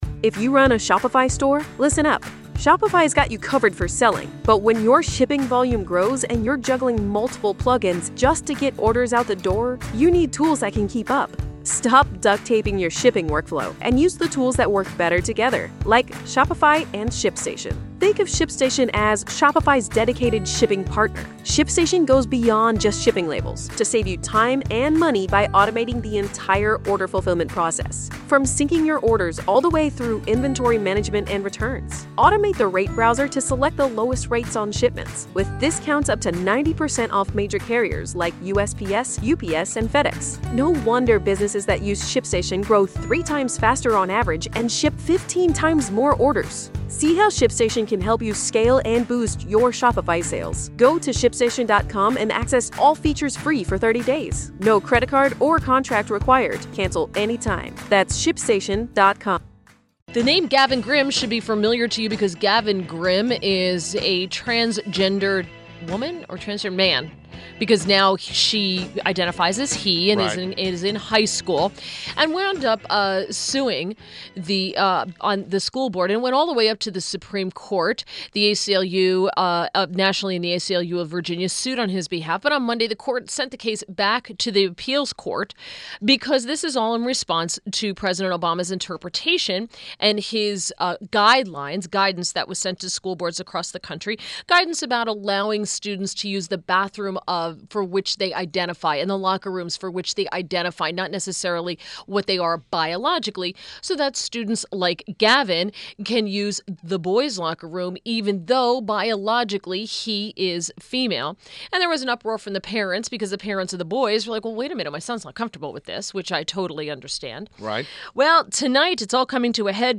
INTERVIEW – LIZ BARRETT – VICE PRESIDENT OF Frederick County Public Schools board